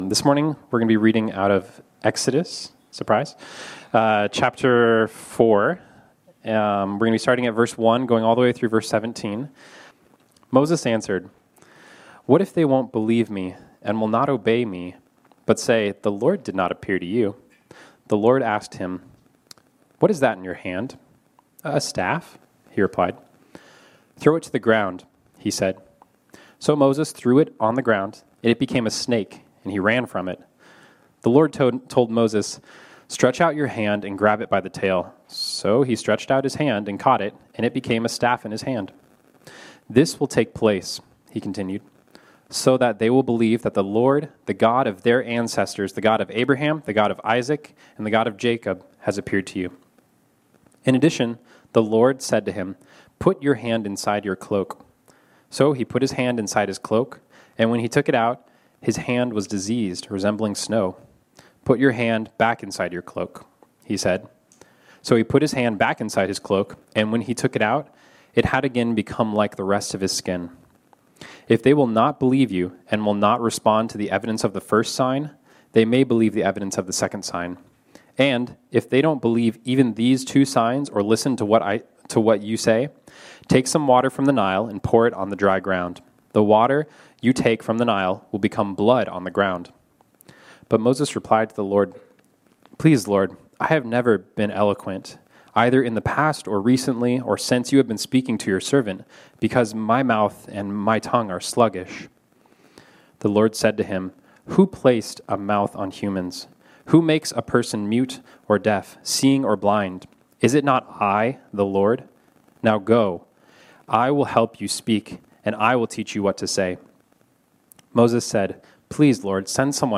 ” our sermon series on the book of Exodus.